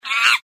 chimp.mp3